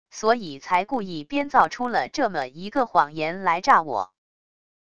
所以才故意编造出了这么一个谎言来诈我wav音频生成系统WAV Audio Player